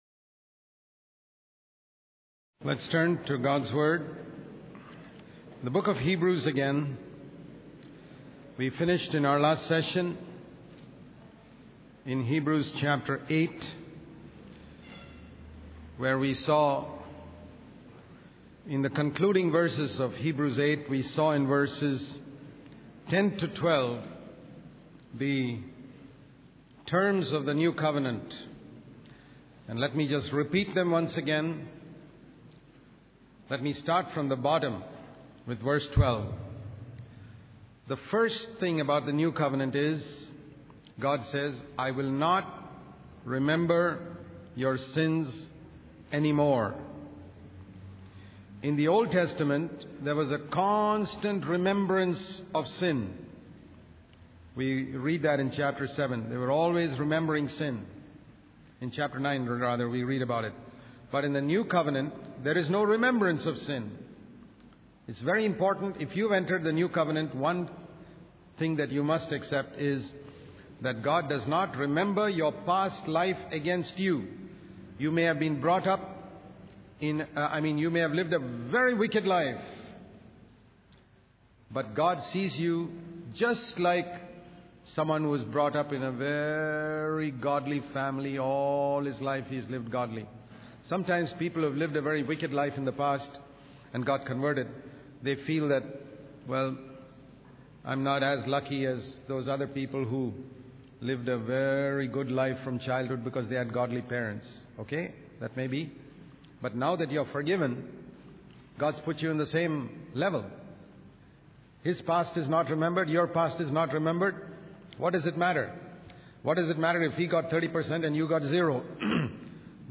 In this sermon, the preacher emphasizes the importance of striving against sin, just as Jesus did.